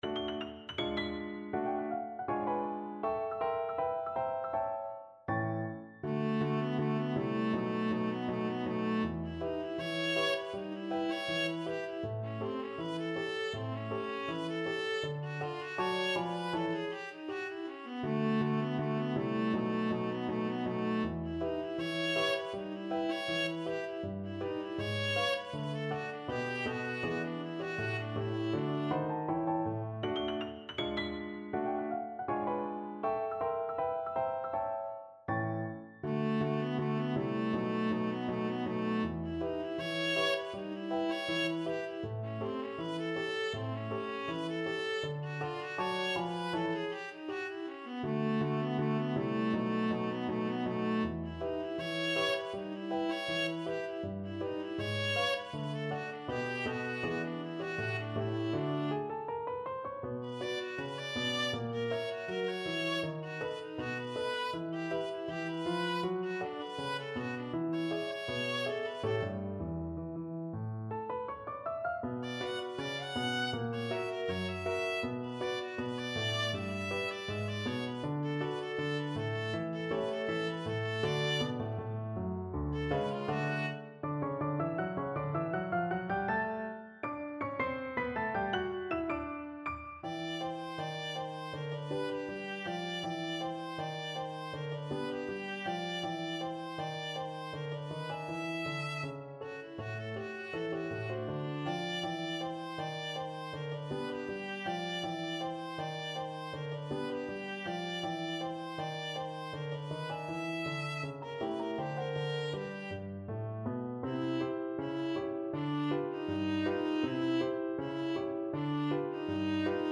Viola version
2/2 (View more 2/2 Music)
Jazz (View more Jazz Viola Music)